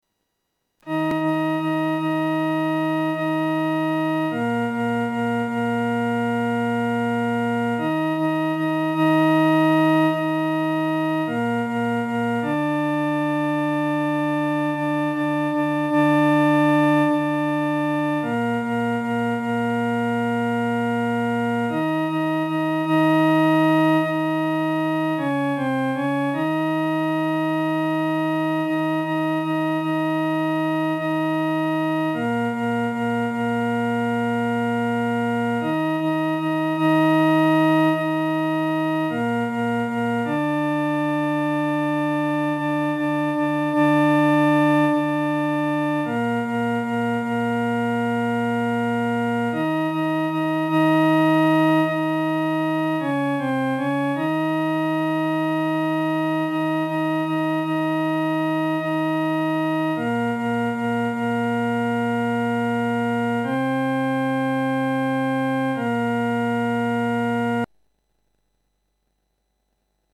伴奏
四声